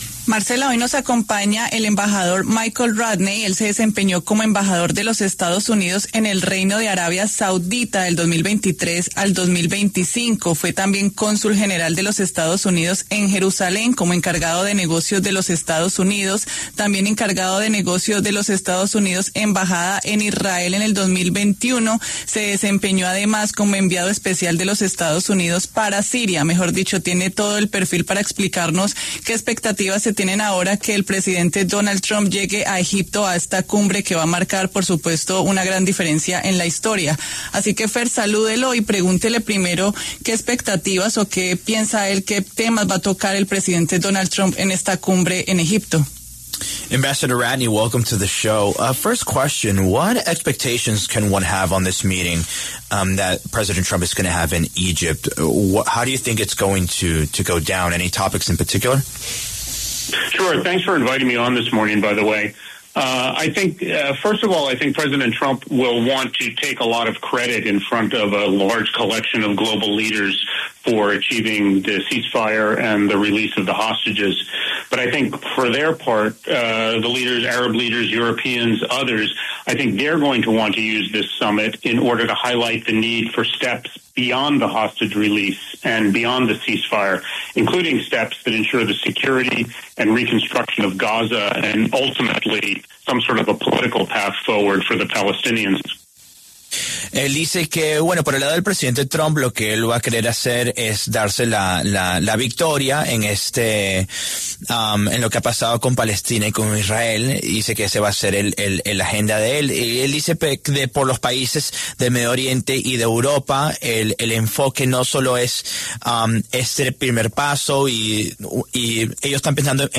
En diálogo con La W, Michael Ratney, quien se desempeñó como embajador de los Estados Unidos en el Reino de Arabia Saudita, analizó la visita de Donald Trump a Medio Oriente luego de que se liberaran a los rehenes por parte de Hamás y los capturados que tenía Israel.